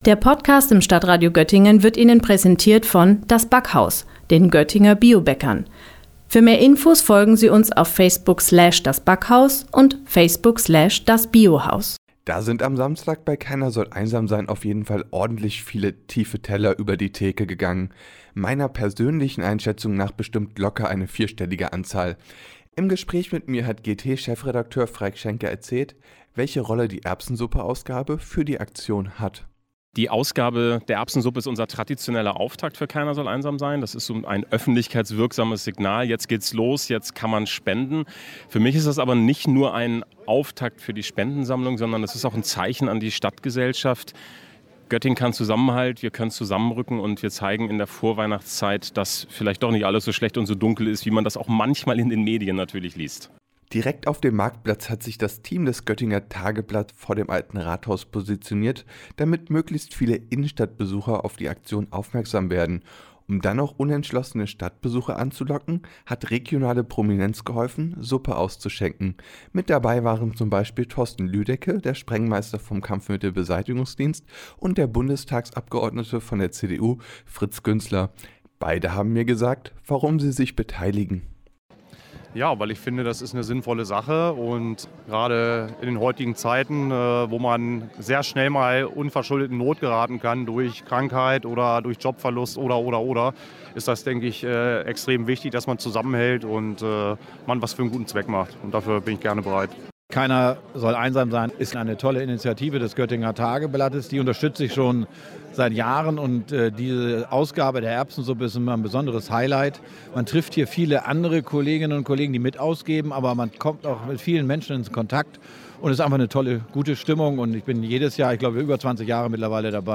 Suppe löffeln für den guten Zweck! Das ging am Samstagvormittag und -mittag in Göttingen auf dem Marktplatz.